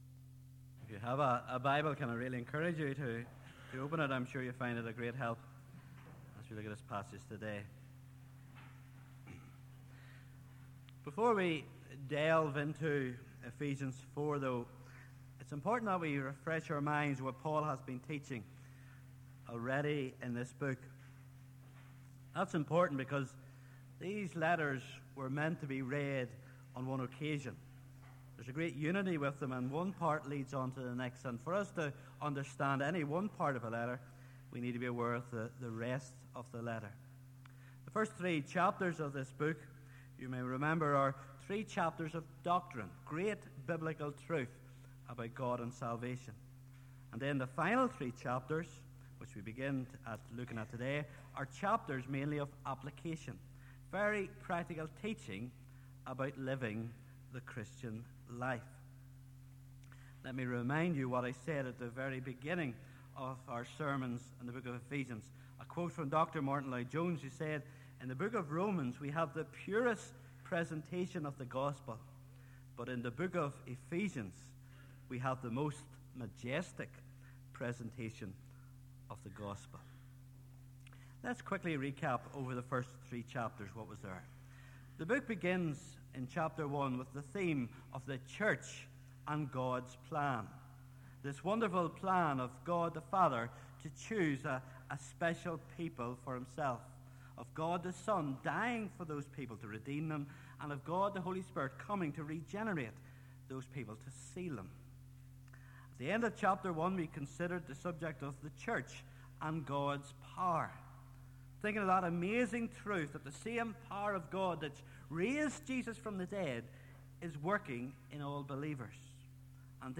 Please note: this sermon was preached before Brookside adopted the English Standard Version as our primary Bible translation, the wording above may differ from what is spoken on the recording.